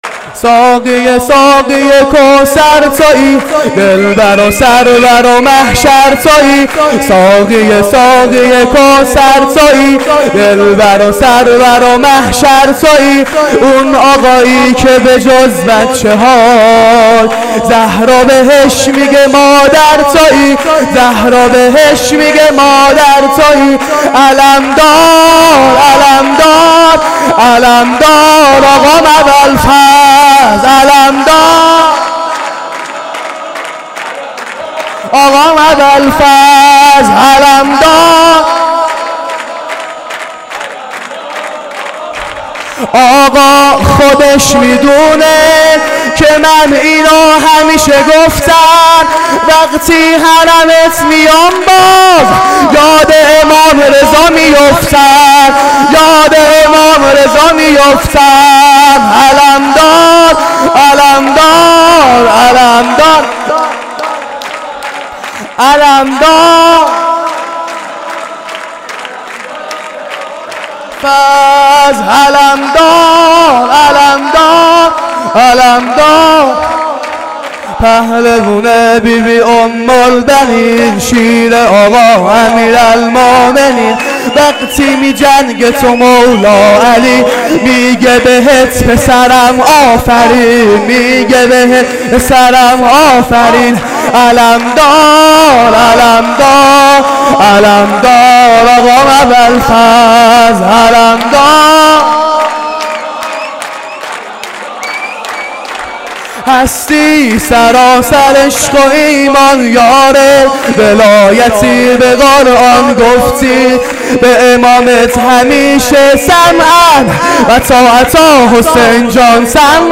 خیمه گاه - هیئت رایة المهدی (عج) قم - سرود | ساقی ساقی کوثر